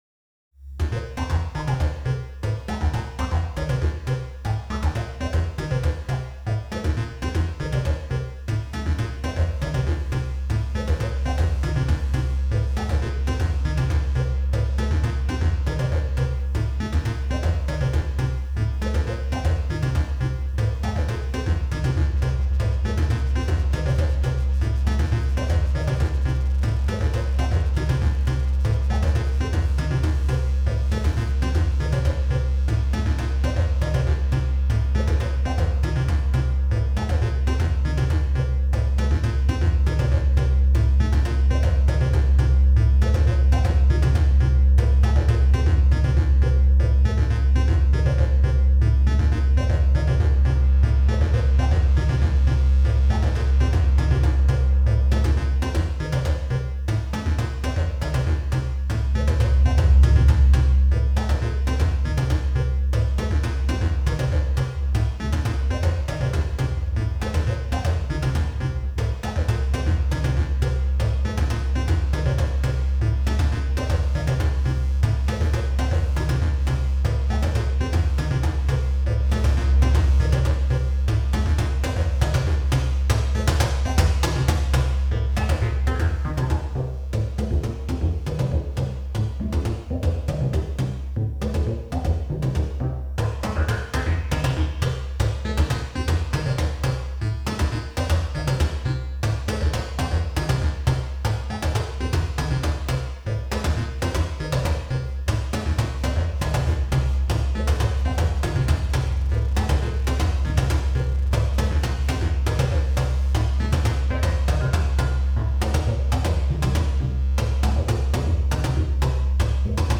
Synthesizer Projekt 2023 | LMG
Projektausflug ins Tonlabor der HAW
In letzterem haben wir nach der Führung die Klänge unserer Synthesizer, die wir in den vorherigen Tagen vorbereitet hatten, professionell aufgenommen und dann noch mithilfe eines Tonbearbeitungsprogramms die entstandenen Tonspuren modifiziert – also zum Beispiel Effekte wie Hall hinzugefügt.